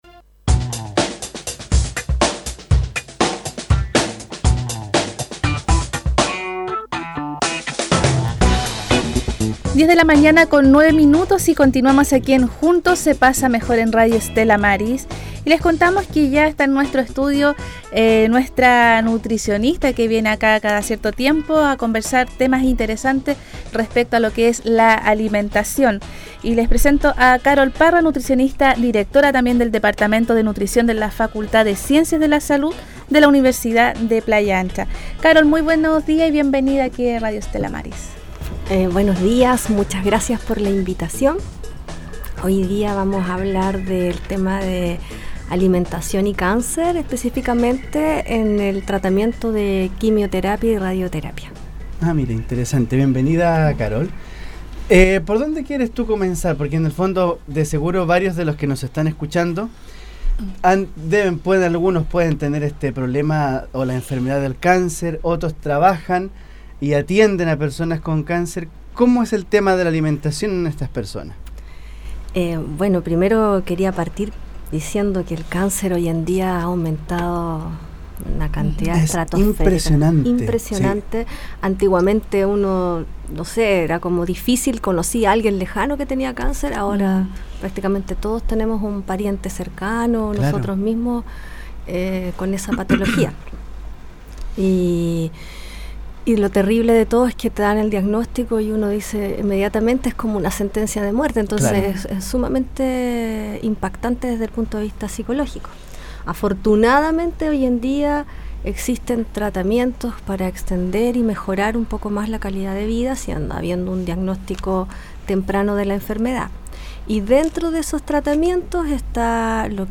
El audio de este diálogo, a continuación: